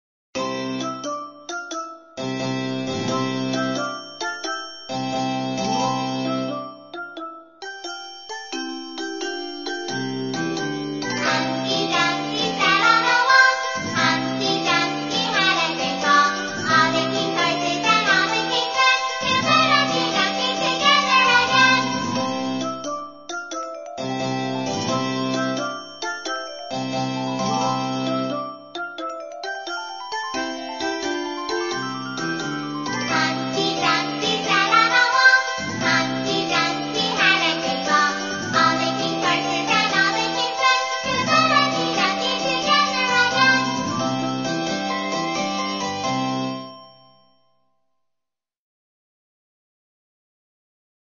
在线英语听力室英语儿歌274首 第77期:Humpty Dumpty的听力文件下载,收录了274首发音地道纯正，音乐节奏活泼动人的英文儿歌，从小培养对英语的爱好，为以后萌娃学习更多的英语知识，打下坚实的基础。